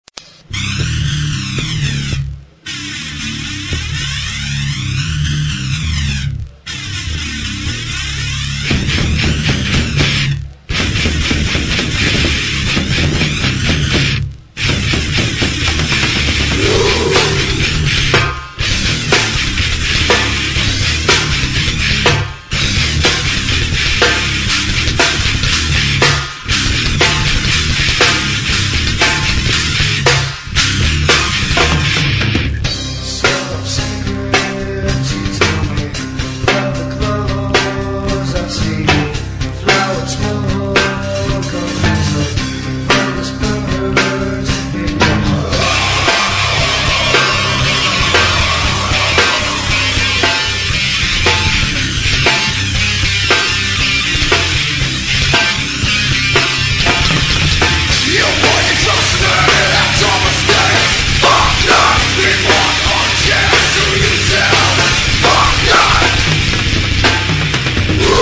demo 1:08 preview (studio recording)